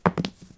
dieThrow3.ogg